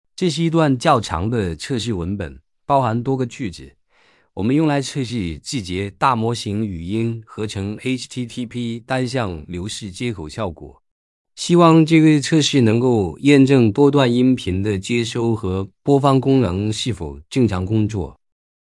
tts_test.mp3